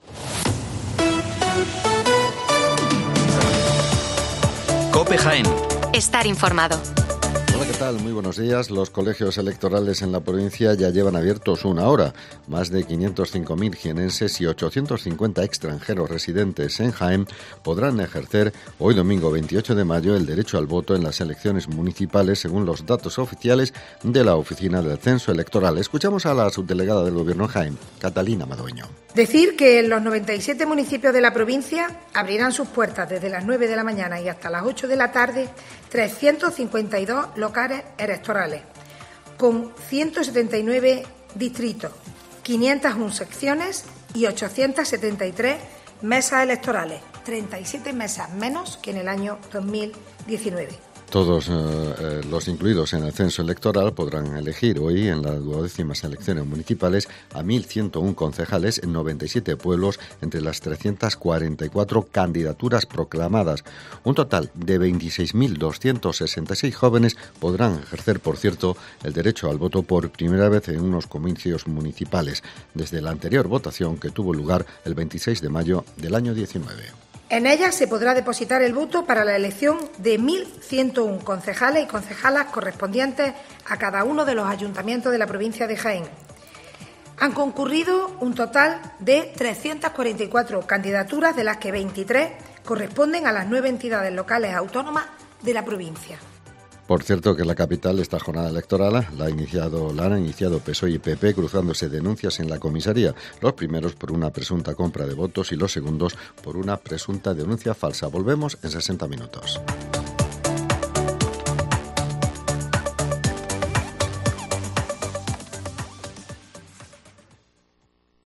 Especial Elecciones Municipales en Jaén. El informativo de las 10:05 horas